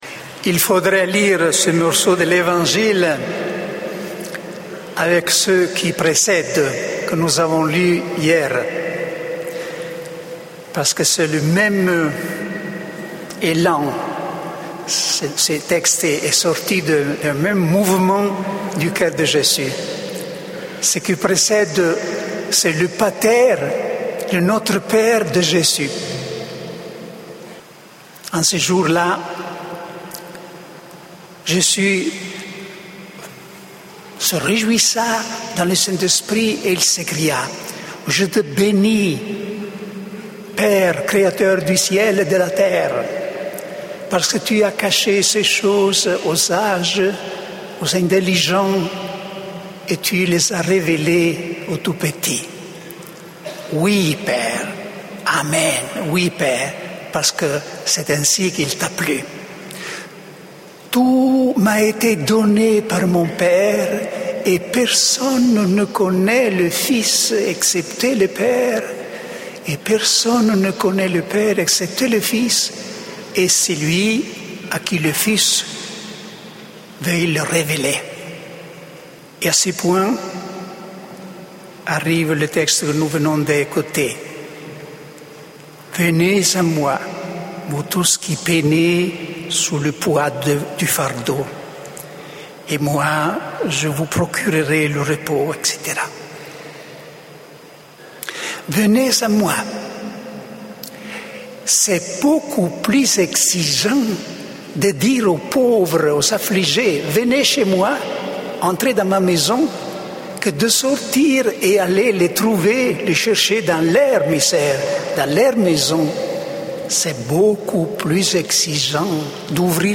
Enregistré en 2011 (Session de la Communauté des Béatitudes - Lourdes 13 au 17 juillet 2011)
Format :MP3 64Kbps Mono